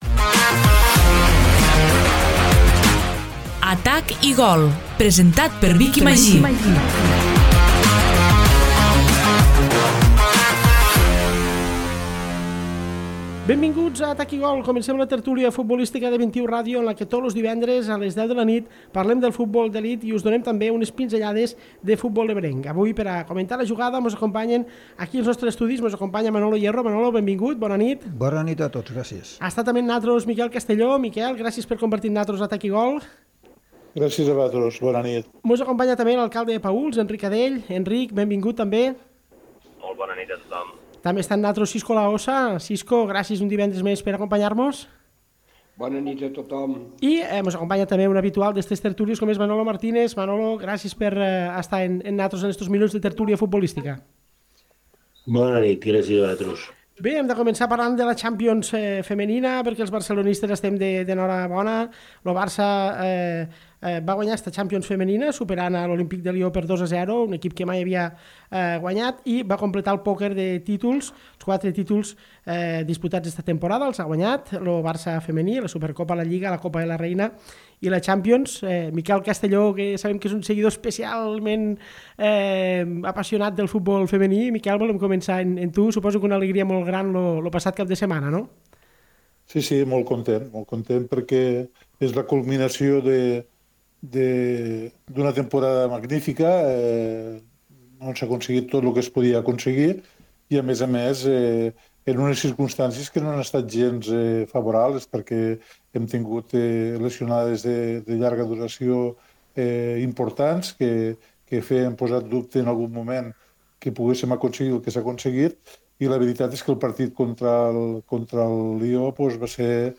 Avui un nou episodi d’Atac i gol, la tertúlia futbolística de 21 Ràdio en què tots els divendres, a les 22.00, parlem del futbol d’elit i us donem també unes pinzellades de futbol ebrenc.